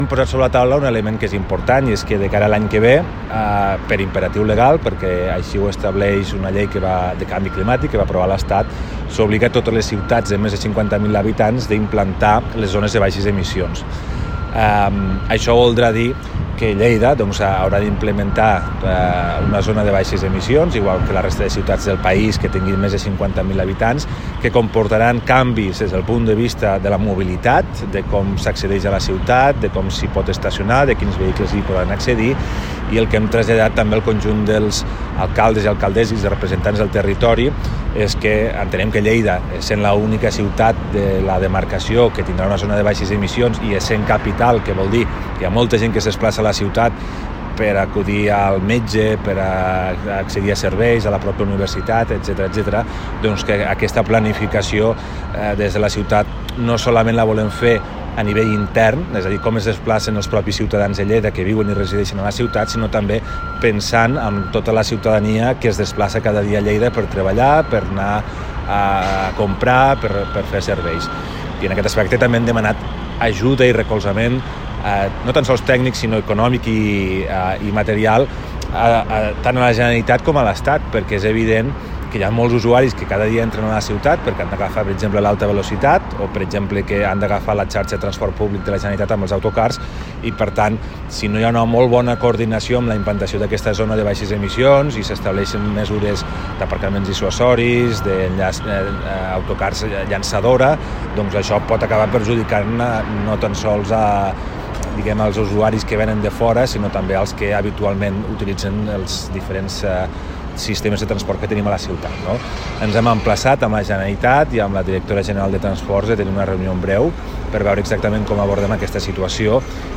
tall-de-veu-del-primer-tinent-dalcalde-toni-postius